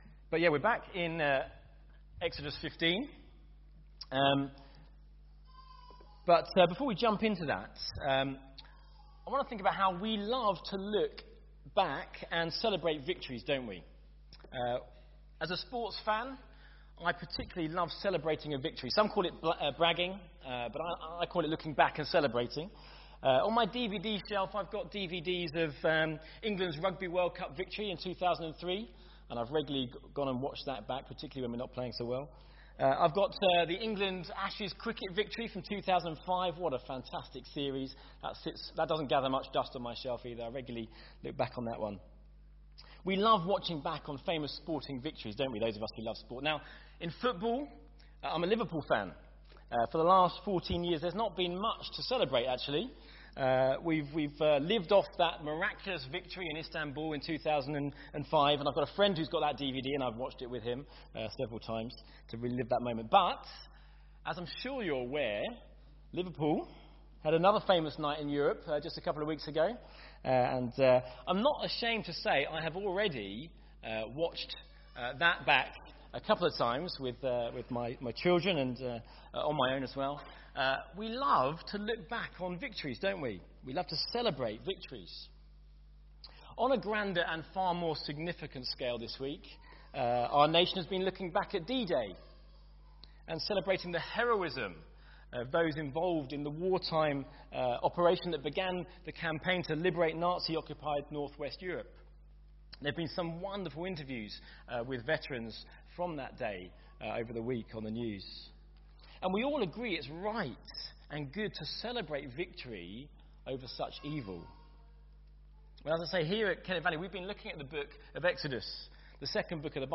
Baptismal Service – June 2019 – Exodus 15:1-21